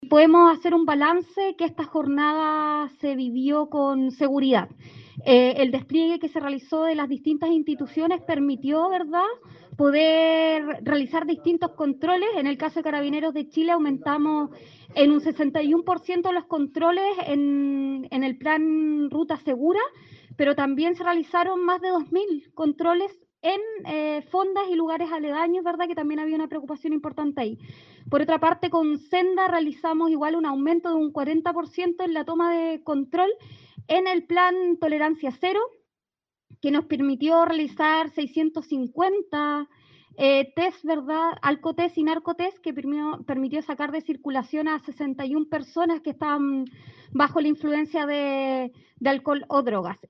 La delegada regional Giovanna Moreira manifestó que la evaluación hecha por los diferentes organismos la mañana de este martes ha sido muy buena, por cuanto no se registraron hechos de mayor connotación en la región.